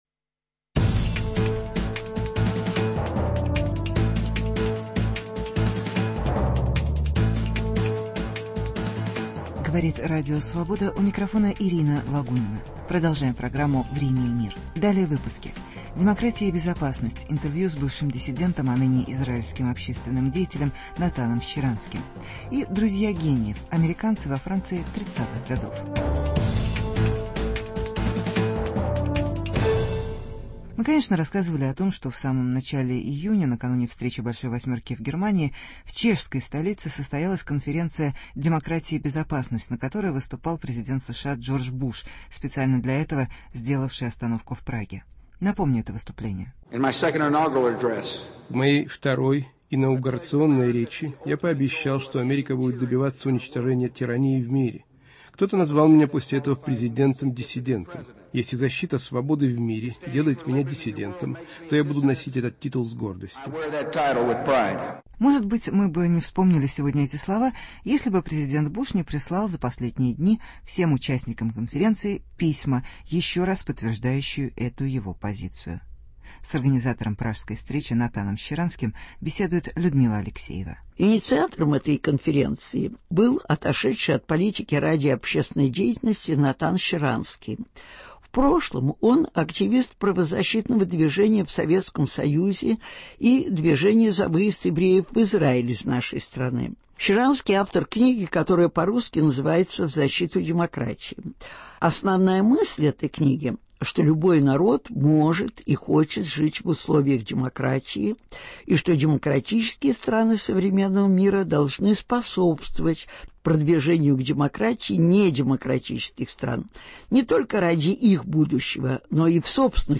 Беседа с Натаном Щаранским.